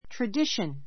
tradition A2 trədíʃən ト ラ ディ ション 名詞 ❶ （語り伝えられた） 伝統, 慣習, しきたり It's a tradition to eat turkey on [at] Thanksgiving.